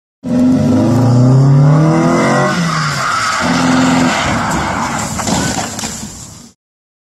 Car Crash Sound Effect Free Download
Car Crash